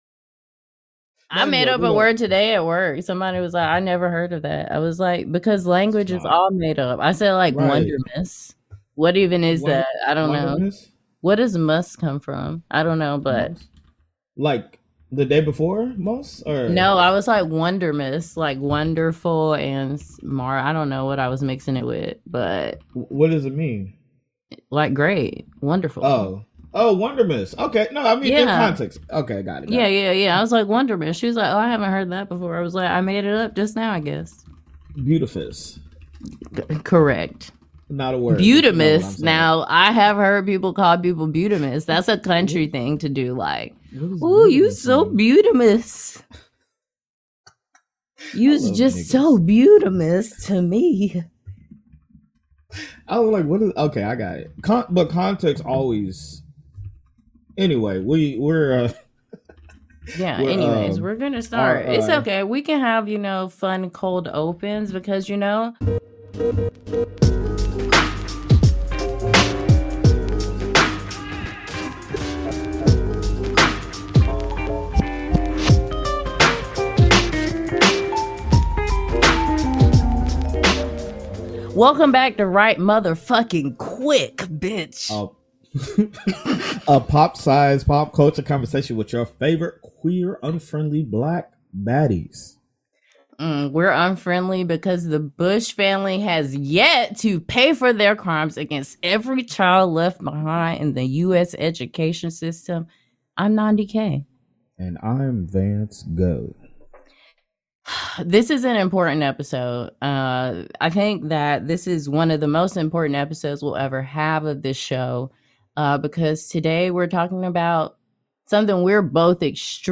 A conversation on illiteracy, what it looks like, and how to fight it.